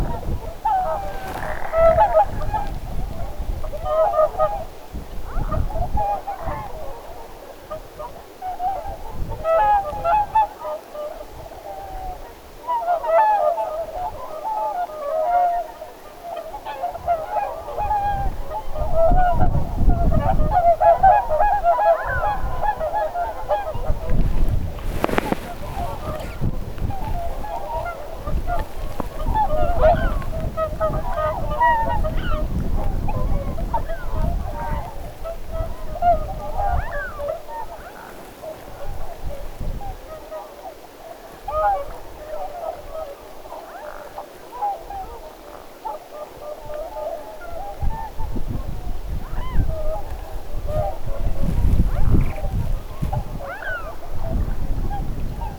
Hippi�isi� oli saaressa. isompi kuva, joka aukeaa uuteen ikkunaan Video: joutsenpaikalla t�n��n ��nite: eilen ��nitetty� kyhmyjoutsenen ��ntely� Eilen kuului paljon kyhmyjoutsenen ��ntely�.
eilen_vahan_kyhmyjoutsenten_aantelya.mp3